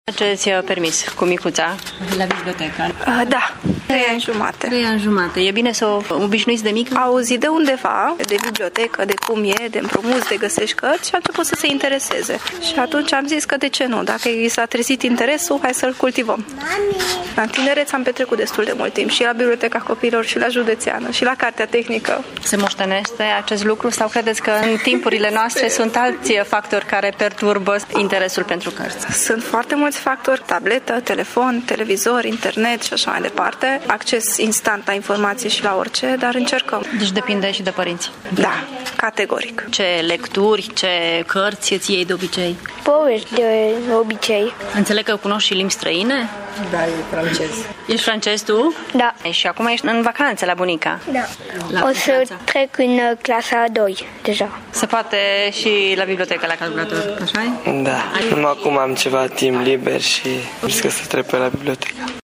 Copiii prind drag de carte dacă și părintele sau bunicii le cultivă de mici astfel de preocupări, cred târgumureșenii: